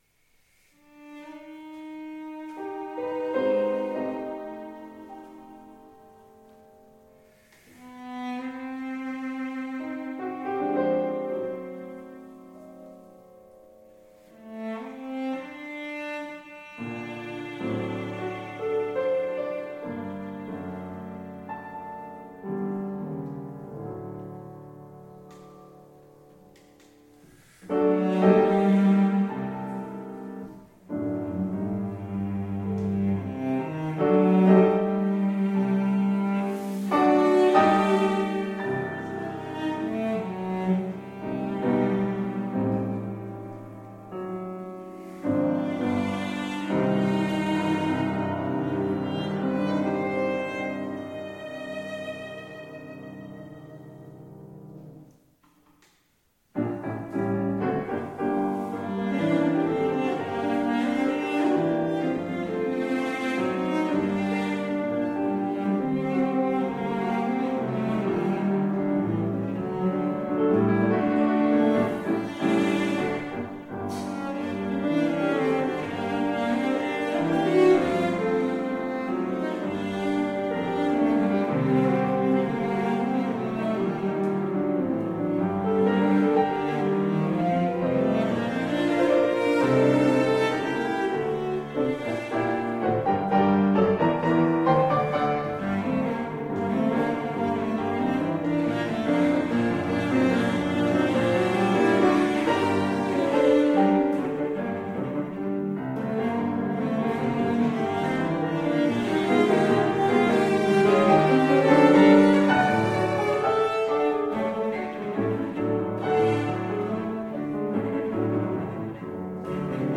Style: Classical
cello
piano
cello-sonata-op-19.mp3